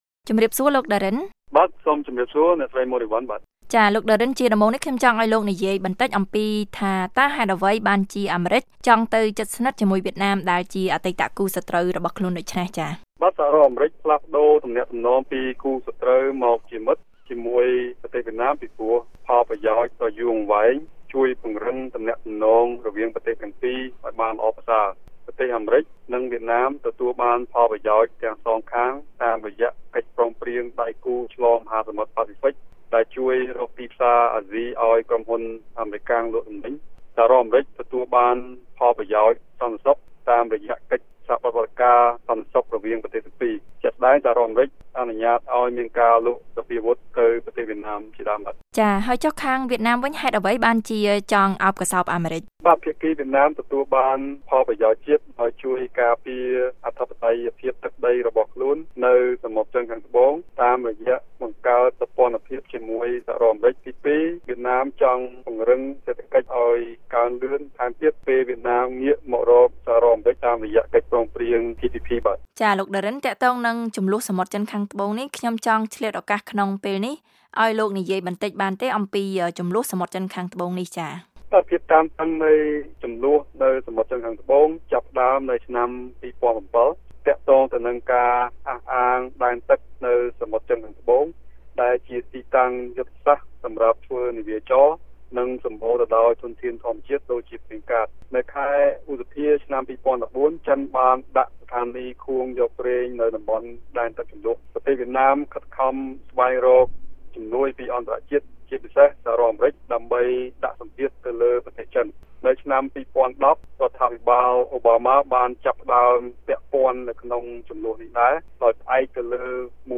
បទសម្ភាសន៍ VOA៖ សហរដ្ឋអាមេរិក និងវៀតណាម បង្កើតចំណងជិតស្និទ្ធ